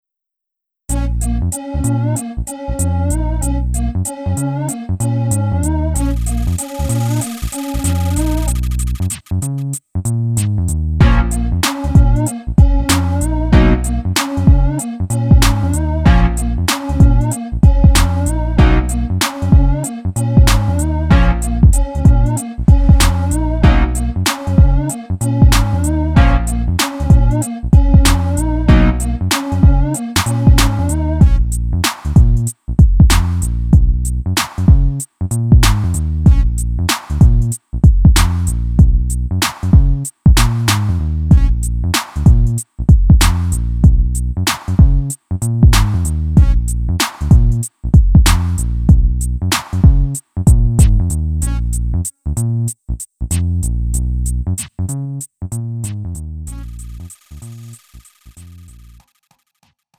음정 원키 3:25
장르 가요 구분